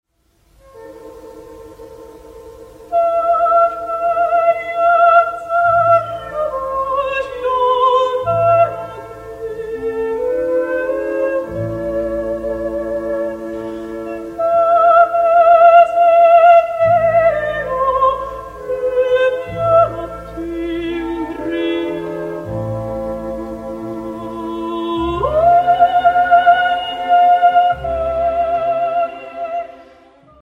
Mara Zampieri - Lyric Soprano